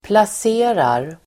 Uttal: [plas'e:rar]